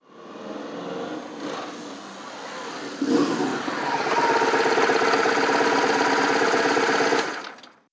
Moto marca BMW llega y para despacio
motocicleta
Sonidos: Transportes